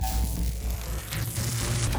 Engine 3 Start.wav